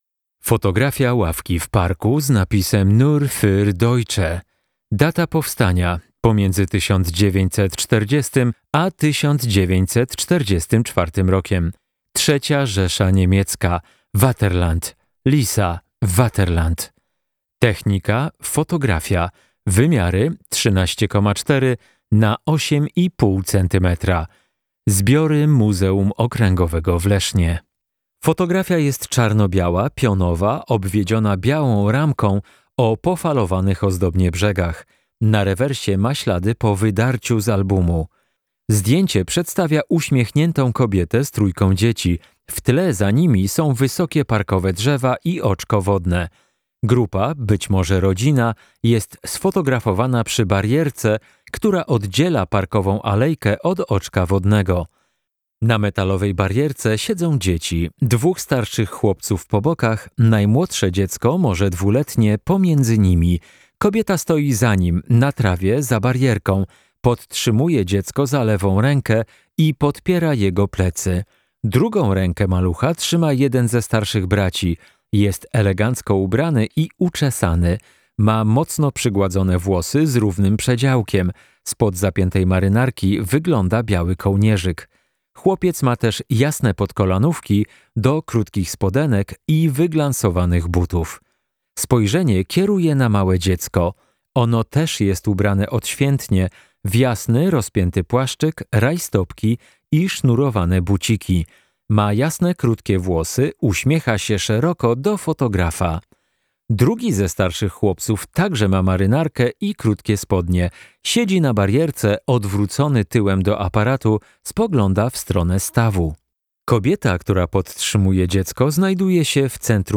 Audiodeskrypcja -